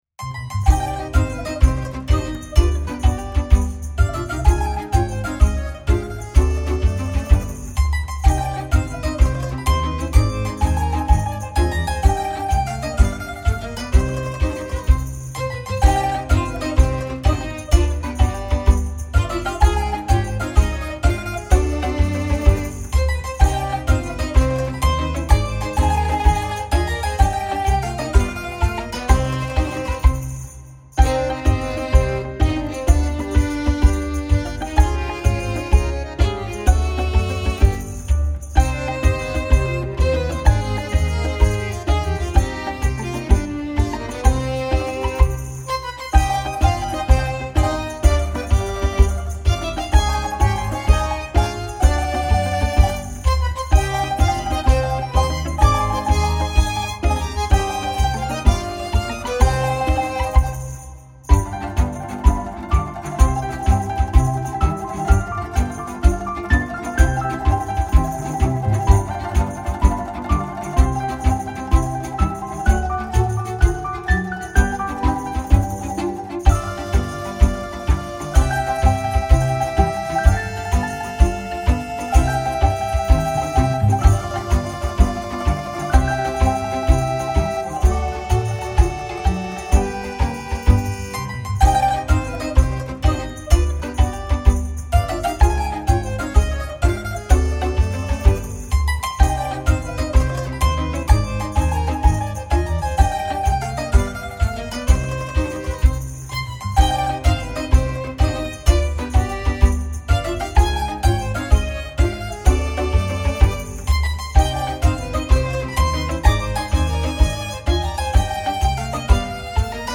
with percussion